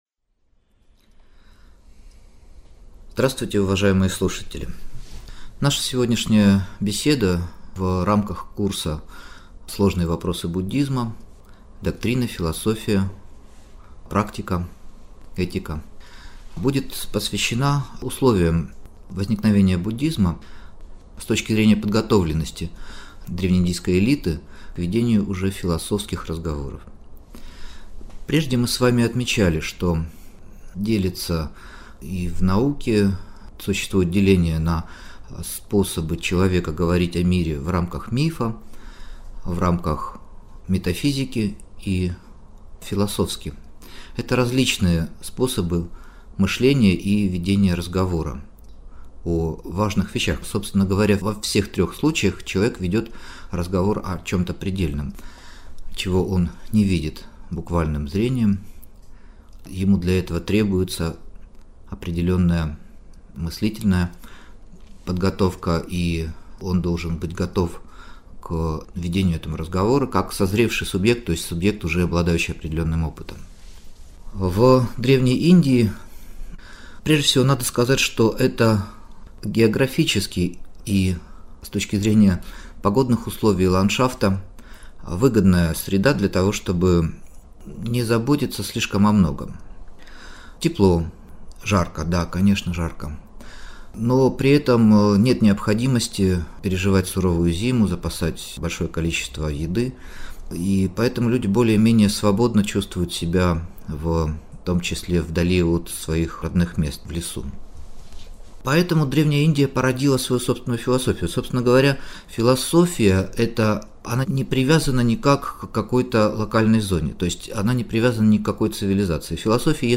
Аудиокнига Древнеиндийские философские школы | Библиотека аудиокниг